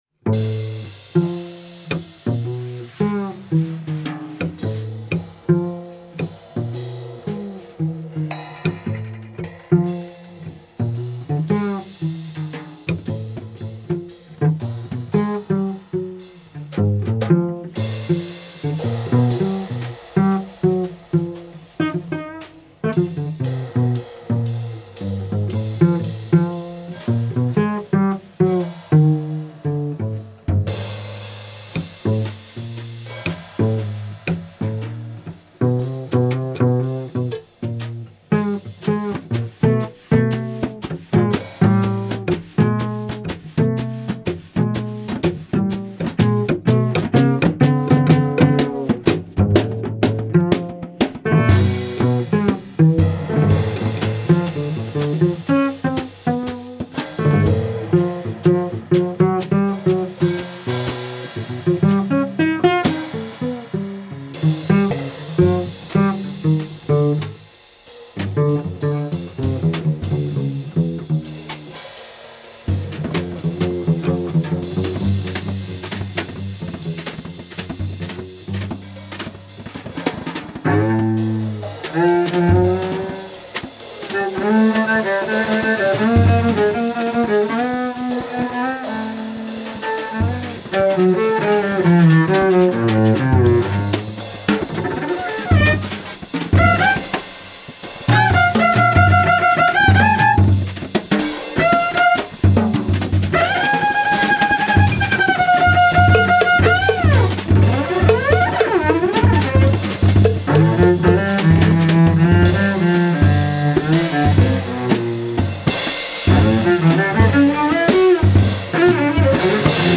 Duo
cello
drums